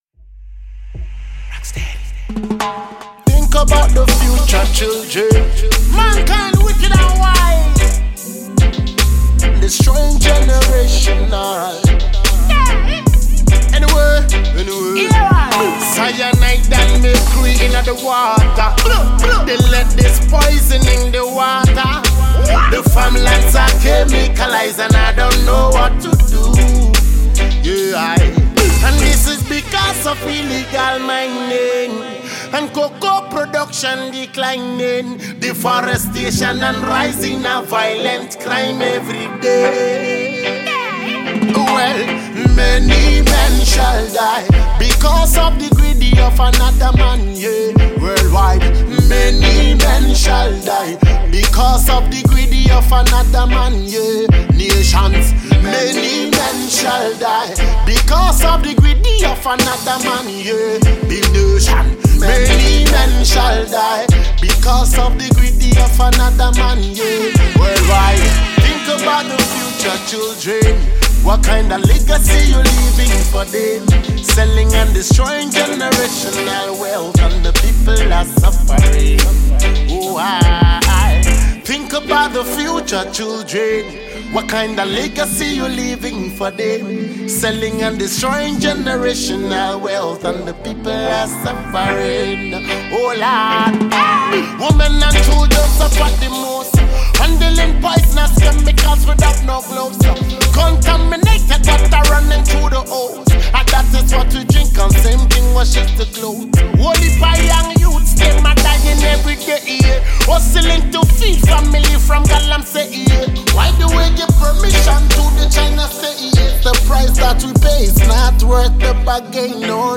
Ghanaian dancehall artist